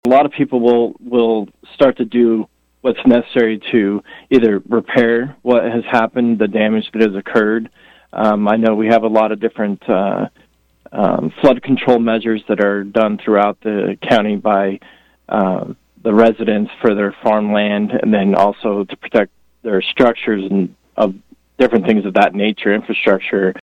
a guest on KVOE’s Morning Show on Tuesday